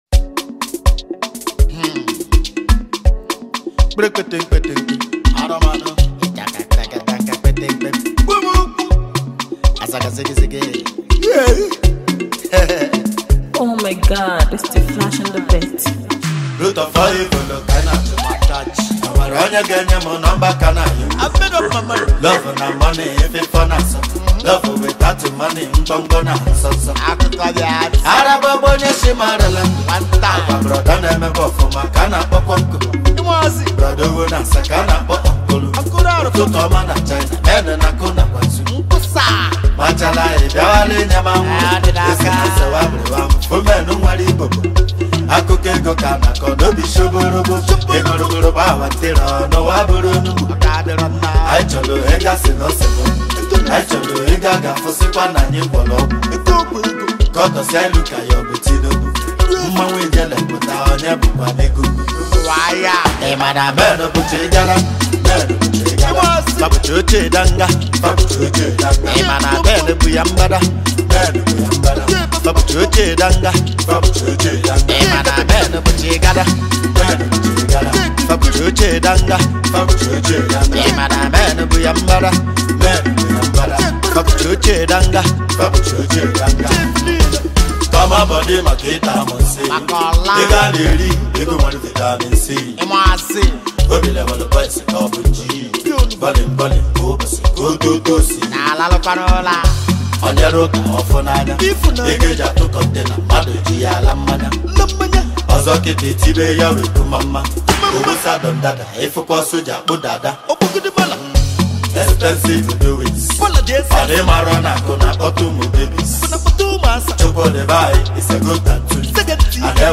Afro-Highlife